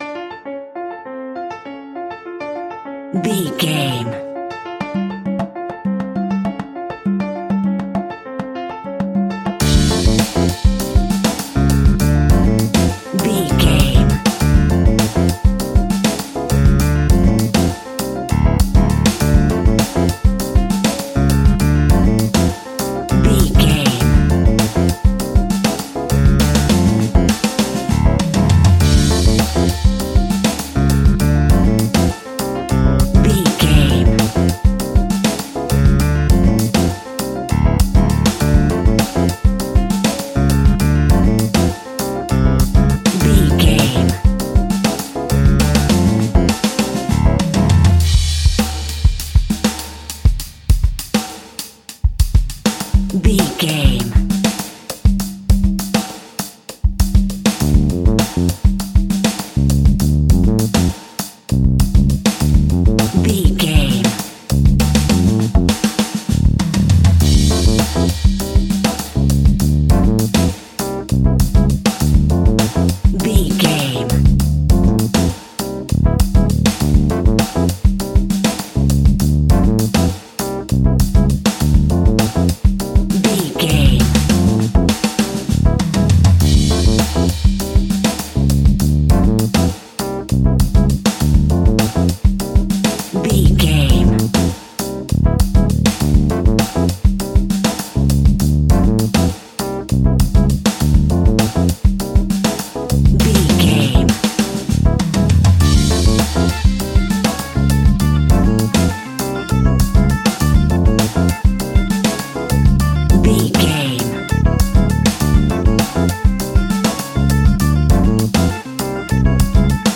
Aeolian/Minor
latin
uptempo
drums
bass guitar
percussion
brass
saxophone
trumpet
fender rhodes
clavinet